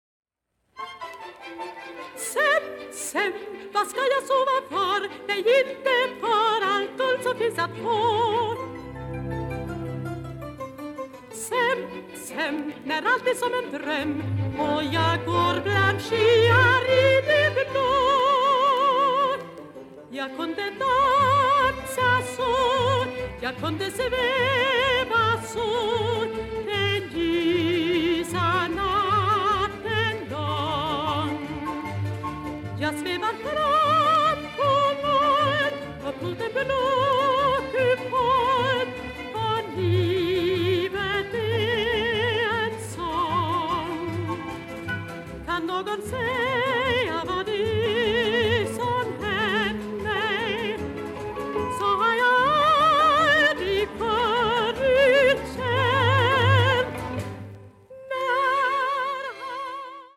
Original Swedish Cast Recording